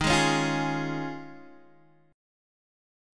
Eb9sus4 chord